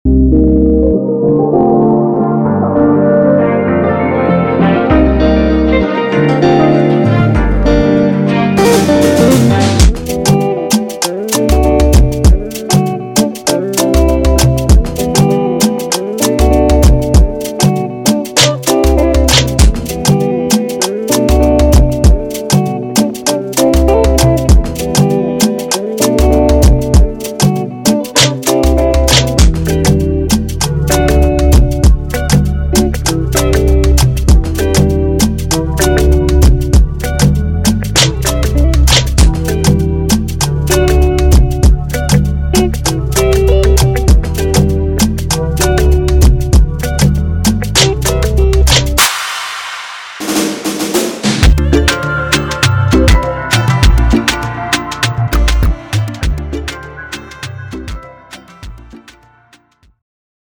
Afropop & Afrobeats
Afro-Cuban influence
the guitarist from the famous West African Afro Cuban band